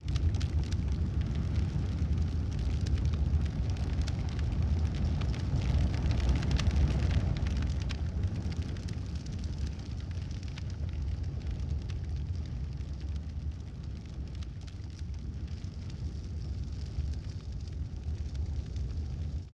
magic_flight_2.mp3